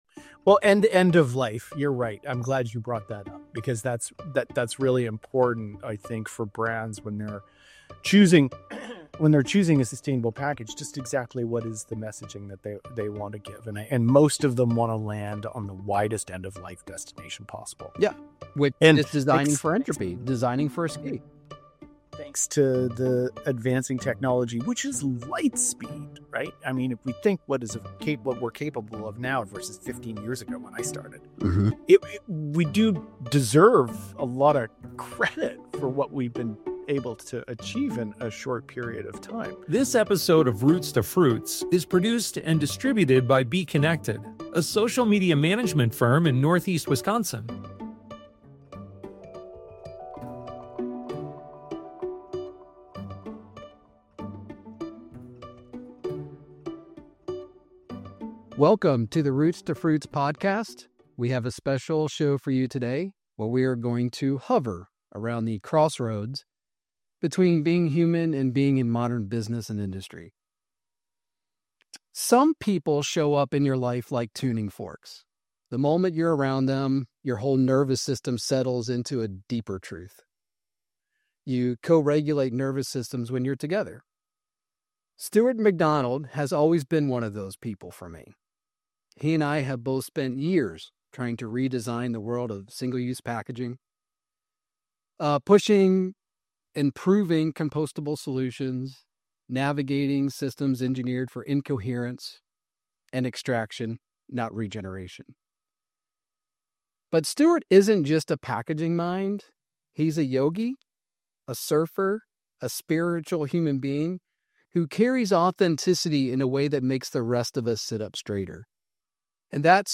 The discussion also delves into the psychological barriers within the industry, the need for trust and authenticity, and the role of mindfulness practices in maintaining balance in a chaotic world.